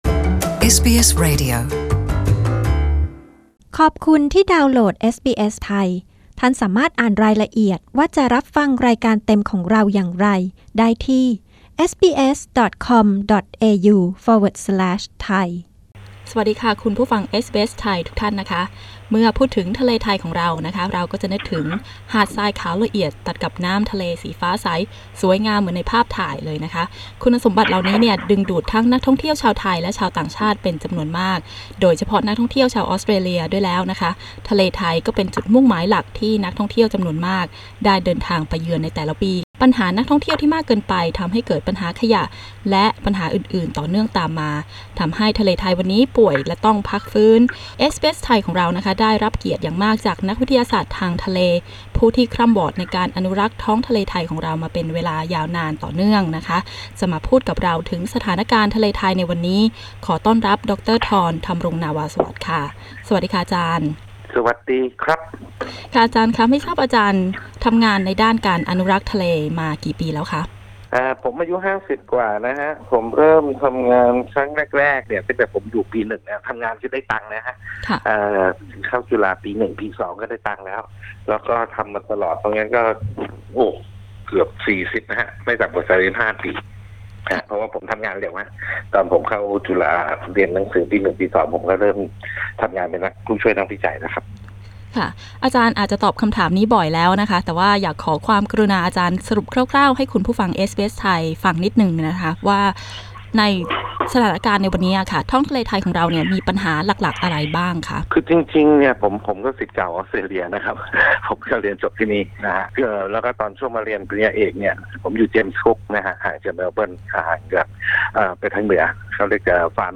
ดร.ธรณ์ ธำรงนาวาสวัสดิ์ นักสมุทรศาสตร์ผู้ผลักดันการปิดปรับปรุงอ่าวมาหยาเปิดเผยกับเอสบีเอสไทยถึงความสำเร็จในการจำกัดจำนวนนักท่องเที่ยวตามแนวทางของพีพีโมเดลซึ่งจะใช้กับการฟื้นฟูสภาพนิเวศน์ทางทะเลที่เกาะอื่นๆ ได้ด้วย พร้อมทั้งแนะนำสิ่งที่นักท่องเที่ยวควรทำเมื่อมาเยือนท้องทะเลไทย